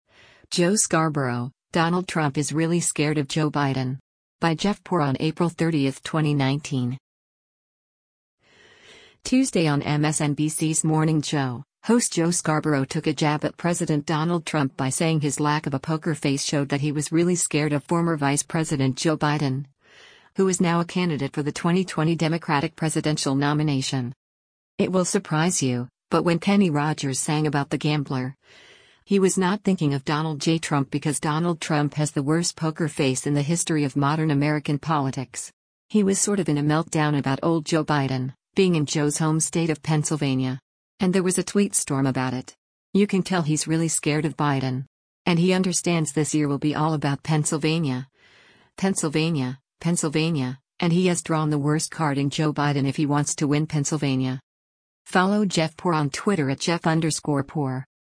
Tuesday on MSNBC’s “Morning Joe,” host Joe Scarborough took a jab at President Donald Trump by saying his lack of a poker face showed that he was “really scared” of former Vice President Joe Biden, who is now a candidate for the 2020 Democratic presidential nomination.